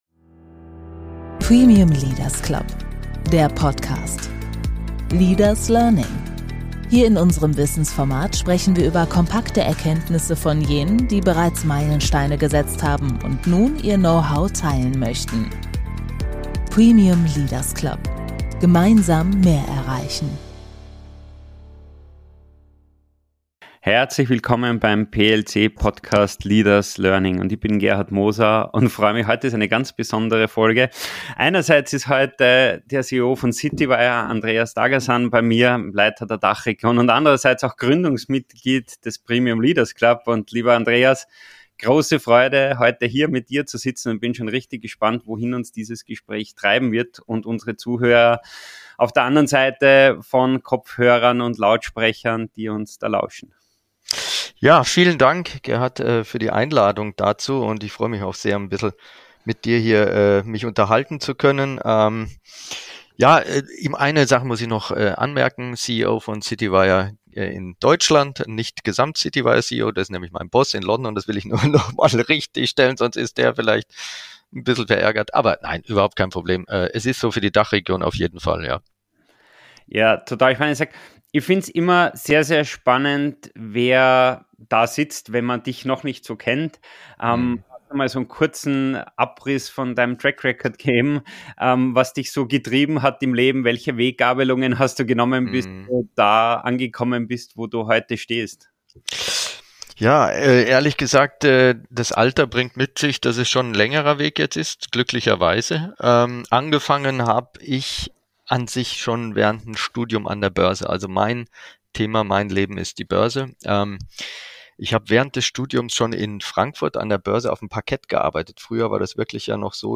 Ein Gespräch mit Tiefgang für alle, die wirtschaftliche Zusammenhänge verstehen – und aktiv gestalten wollen.